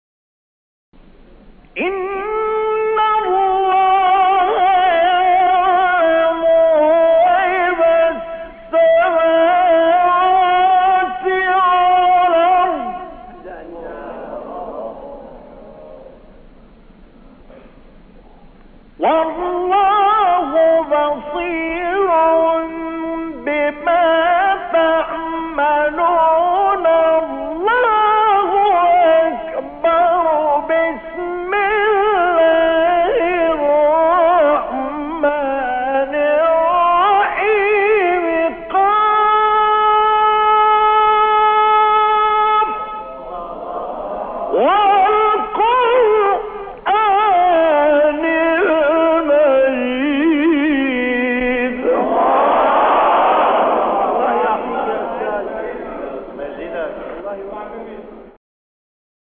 قطعه تلاوت تقلیدی از استاد مصطفی اسماعیل